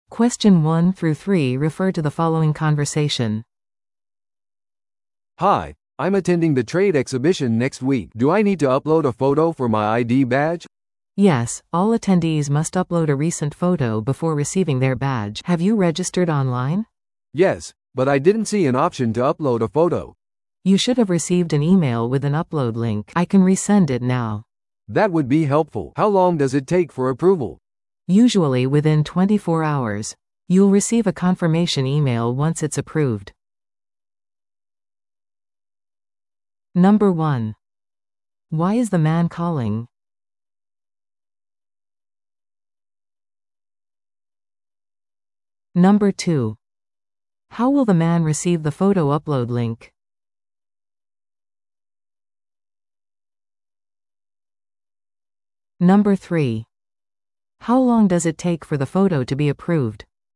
No.1. Why is the man calling?